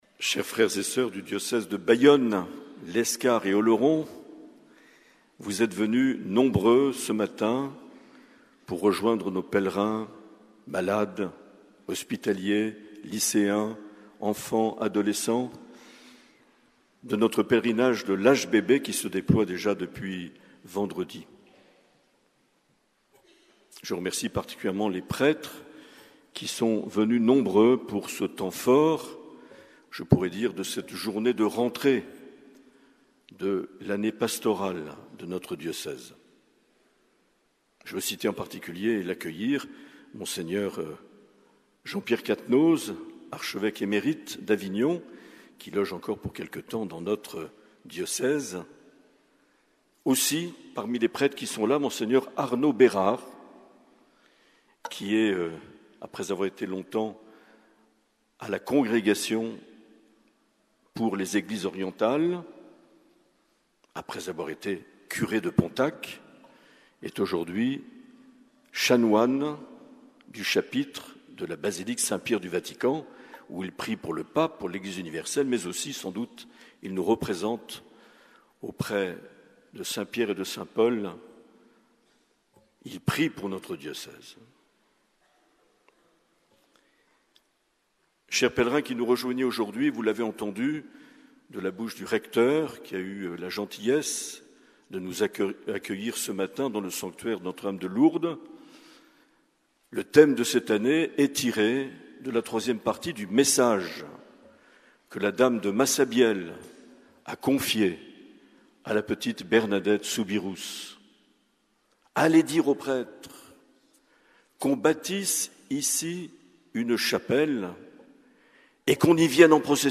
15 septembre 2024 - Pèlerinage diocésain à Lourdes
Homélie de Mgr Marc Aillet.